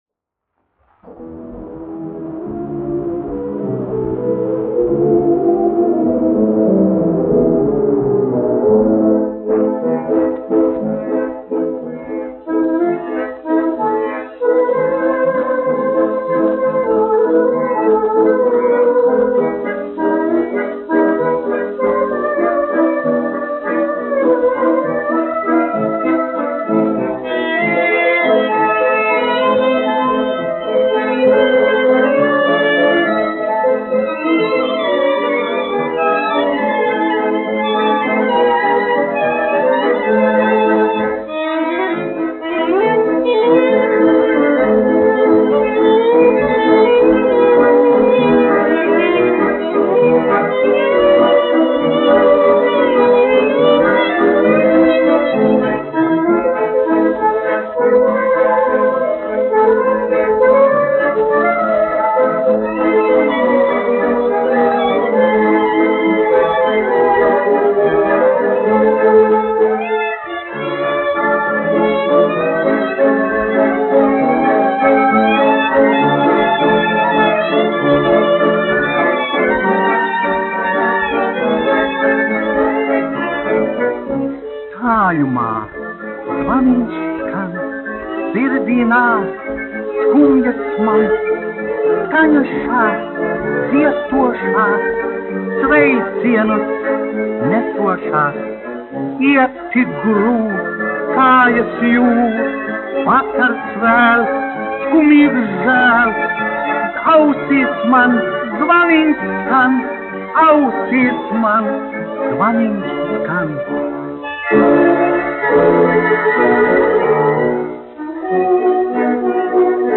1 skpl. : analogs, 78 apgr/min, mono ; 25 cm
Populārā mūzika
Latvijas vēsturiskie šellaka skaņuplašu ieraksti (Kolekcija)